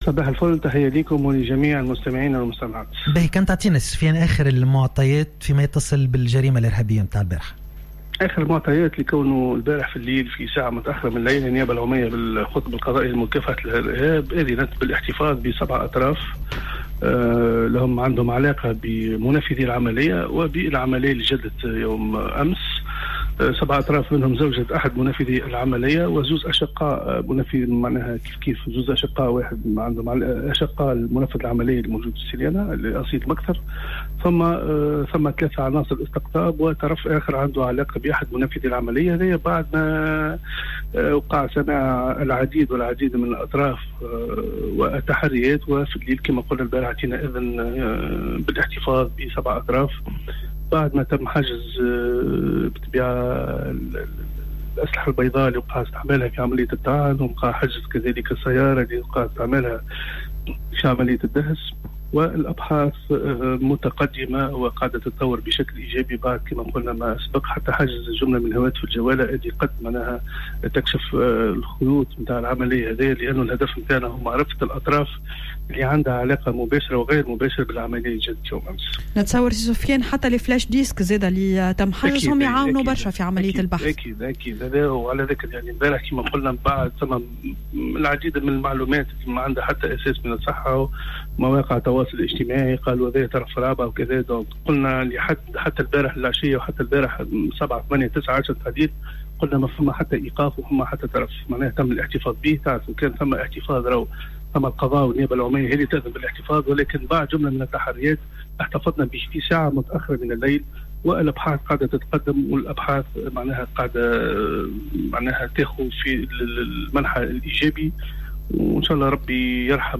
Intervenant sur les ondes de Jawhara FM